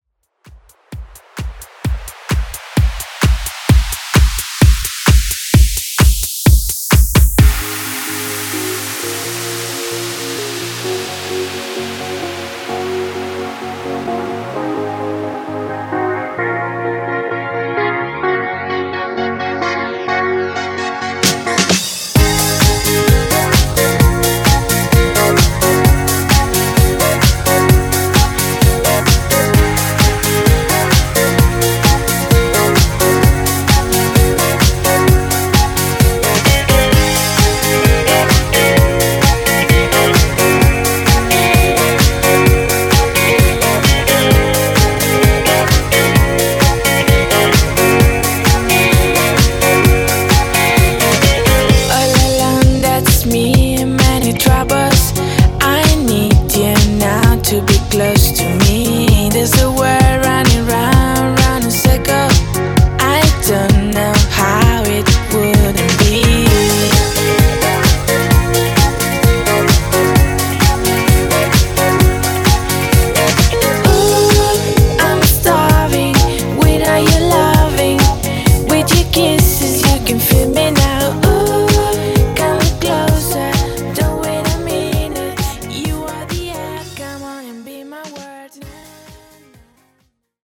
BOOTLEG , DANCE , MASHUPS 128 Clean